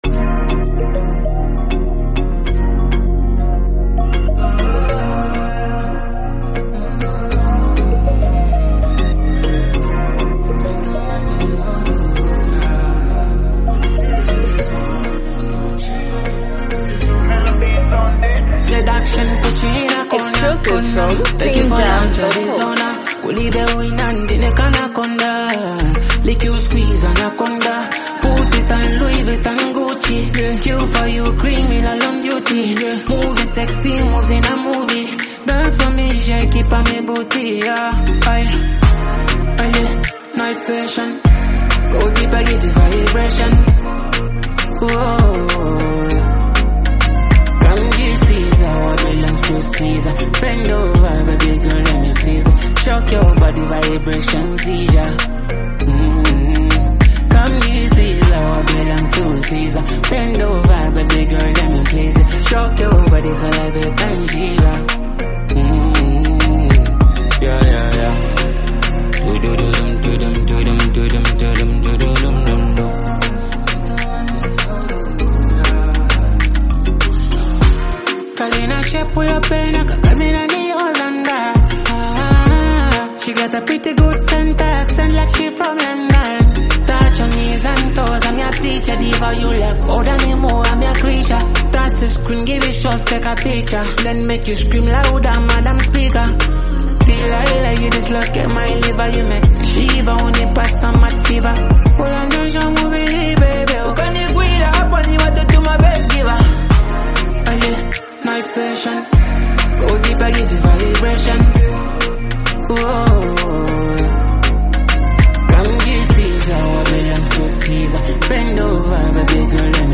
Through bold lyrics and a commanding flow
energetic and assertive